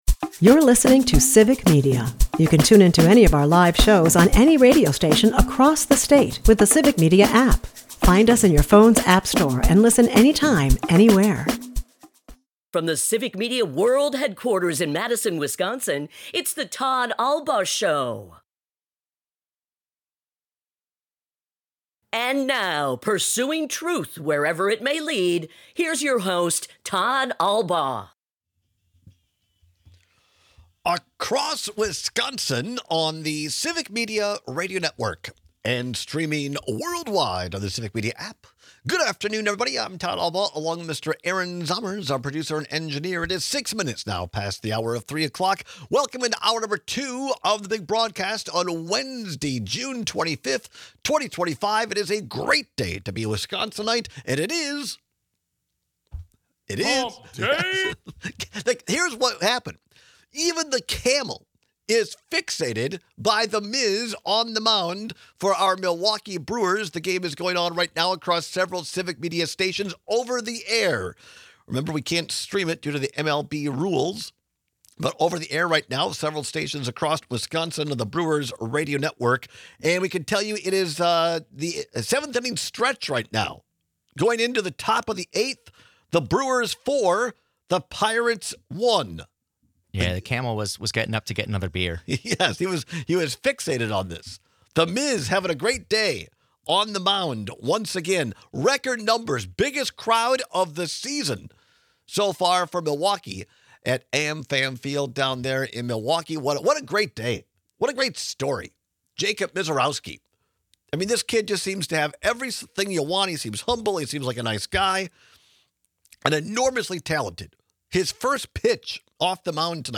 We take texts and calls on whether you’d rather have stained floors or cold feet.&nbsp